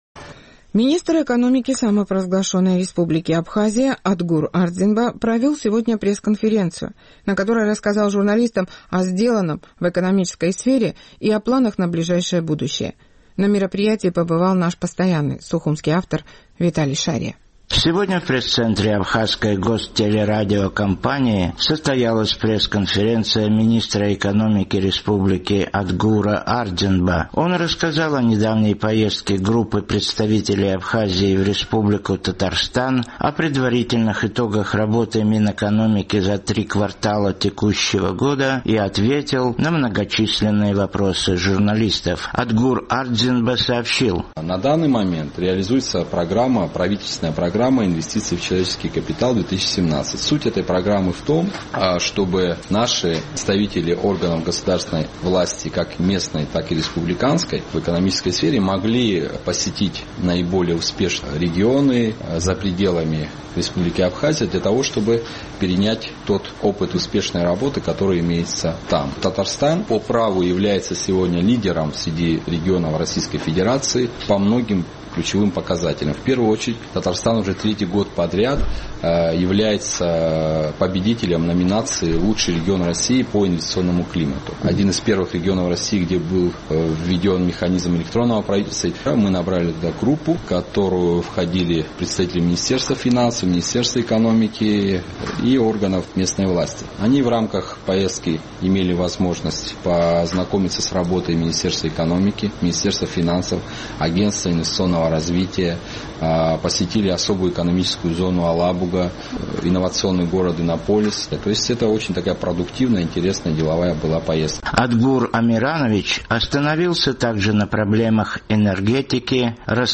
Министр экономики Абхазии Адгур Ардзинба провел сегодня пресс-конференцию, на которой рассказал журналистам о сделанном в экономической сфере, и о планах на ближайшее будущее.